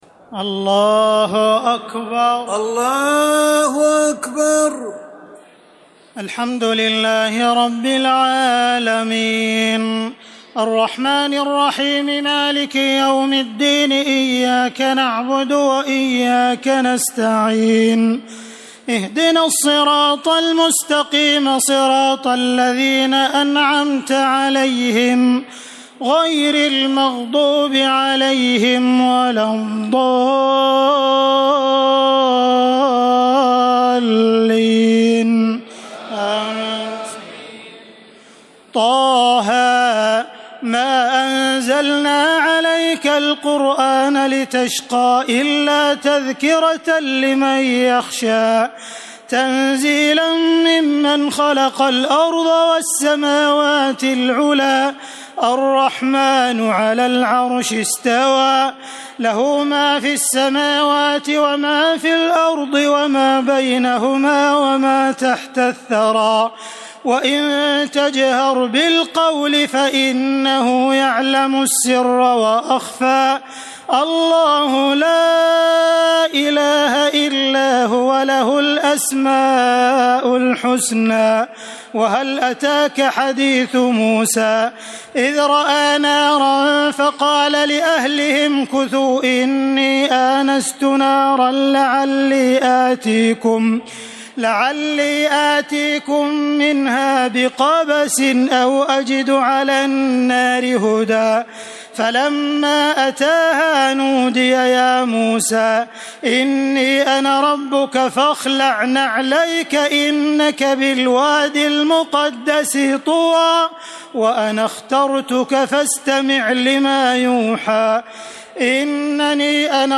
تراويح الليلة الخامسة عشر رمضان 1432هـ سورة طه كاملة Taraweeh 15 st night Ramadan 1432H from Surah Taa-Haa > تراويح الحرم المكي عام 1432 🕋 > التراويح - تلاوات الحرمين